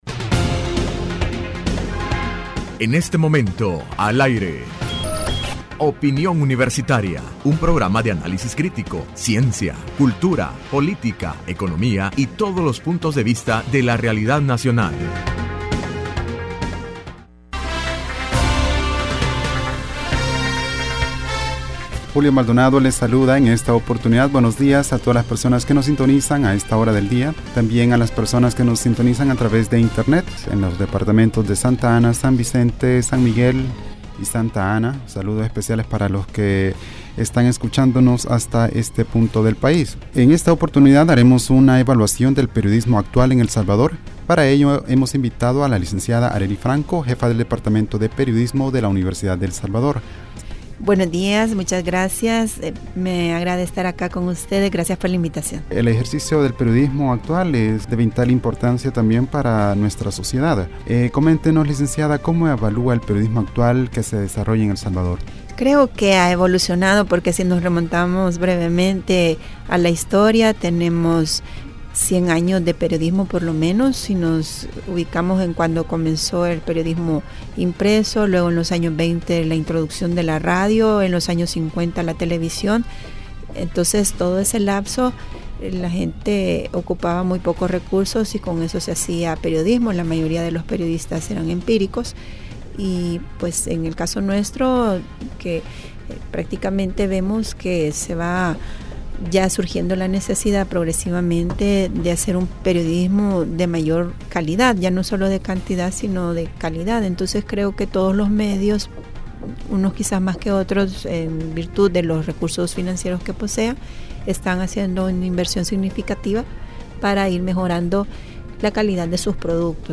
Entrevista opinión Universitaria(4 Mayo 2016) : Análisis del periodismo en El Salvador y la celebración del 61 aniversario del Departamento de Periodismo de la UES